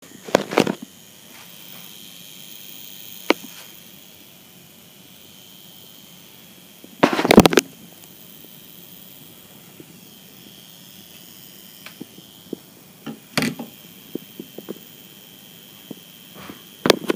Alors voici le son du groupe, puis le concerto lisseur et compteur électrique.
En fait, ce sont tous les transfos (bloc d'alimentation des ordinateurs, alimentation tour d'ordinateur, etc) qui chantent ! ça fait peur.
lisseur-1-.mp3